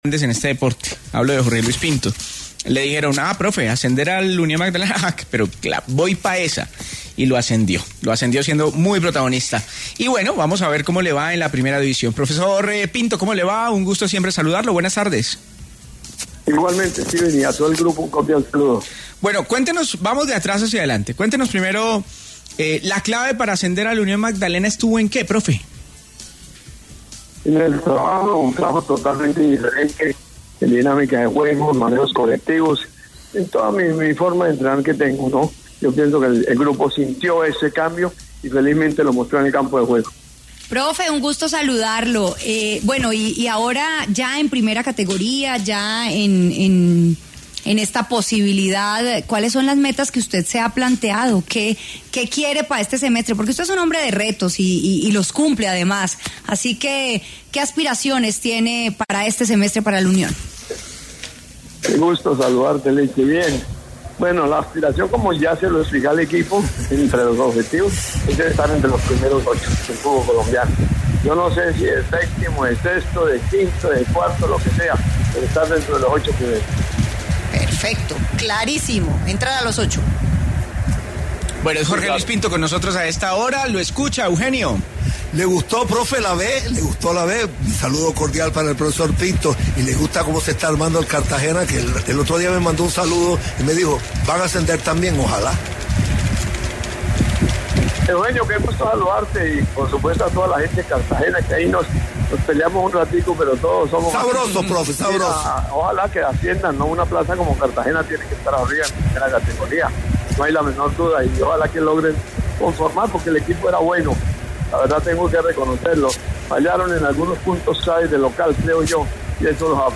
Jorge Luis Pinto, director técnico del Unión Magdalena, se refirió, en diálogo con El VBar de Caracol Radio, al ataque que sufrió el bus de Millonarios el pasado viernes, viéndose afectado el portero Iván Mauricio Arboleda.